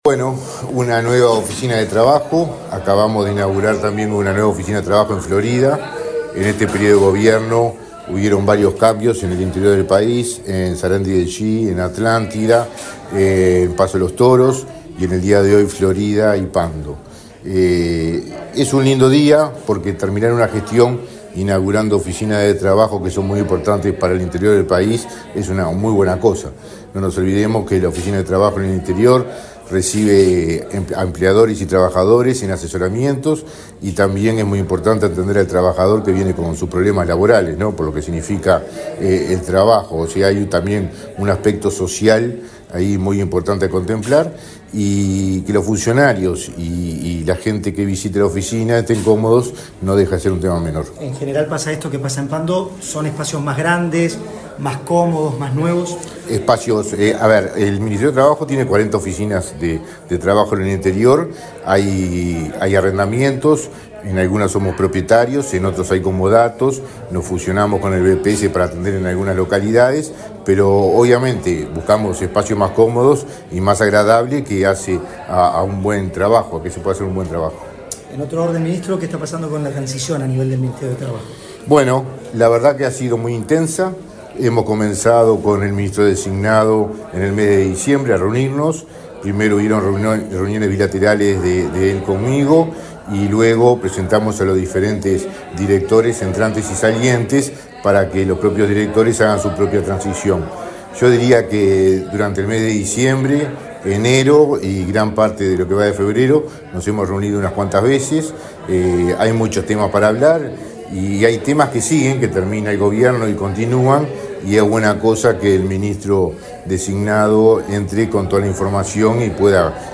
Declaraciones del ministro de Trabajo, Mario Arizti
El ministro de Trabajo, Mario Arizti, dialogó con la prensa, luego de participar en la inauguración de una oficina de esa cartera en la ciudad de